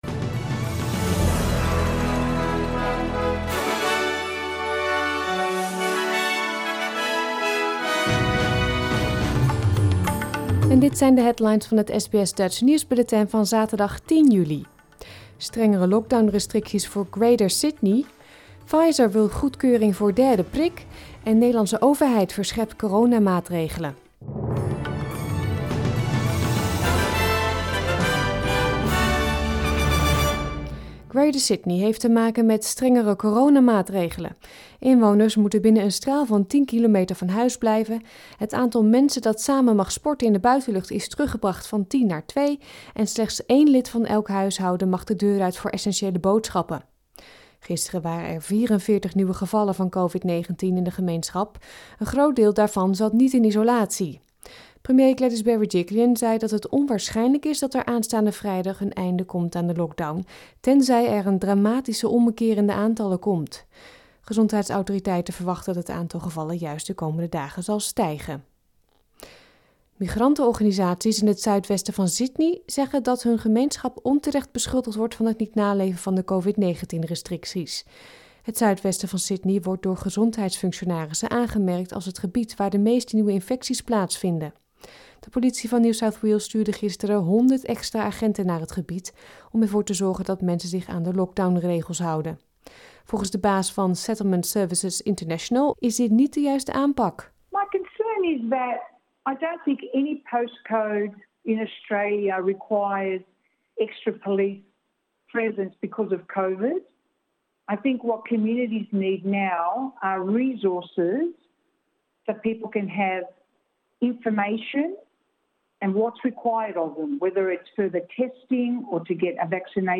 Nederlands/Australisch SBS Dutch nieuwsbulletin van zaterdag 10 juli 2021